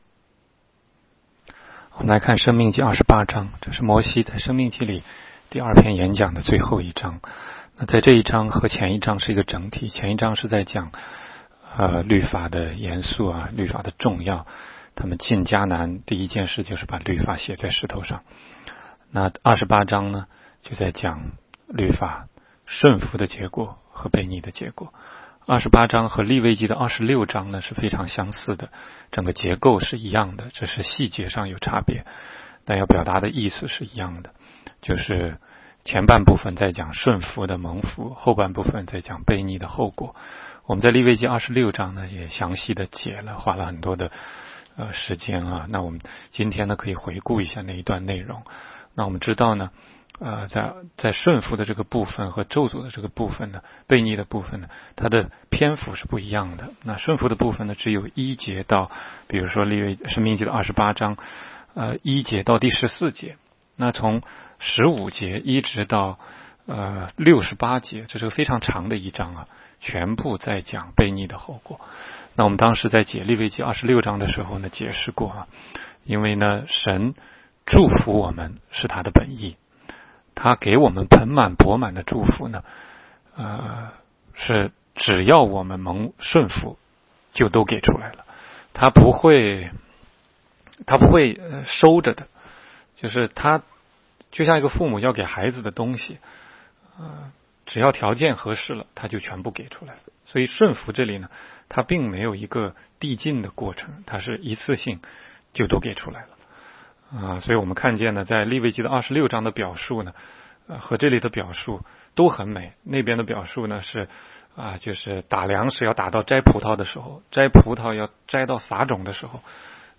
16街讲道录音 - 每日读经-《申命记》28章